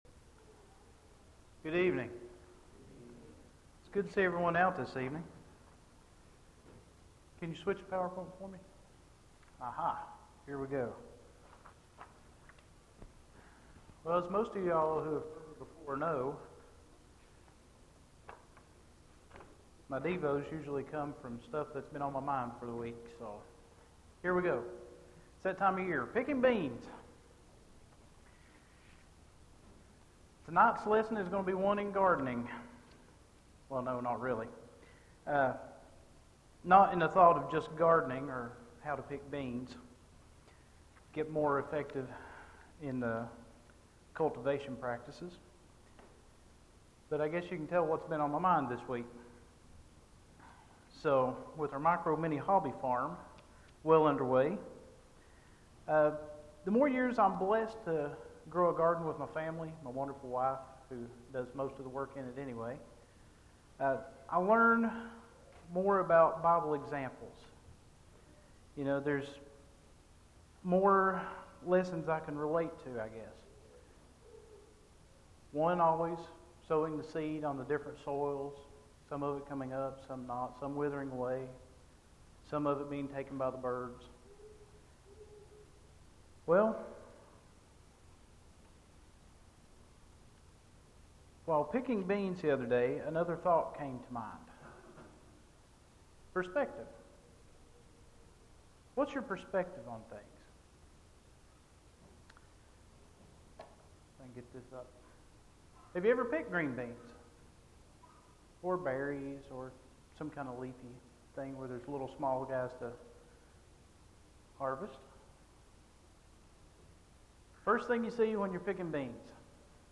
Filed Under: Featured, Lesson Audio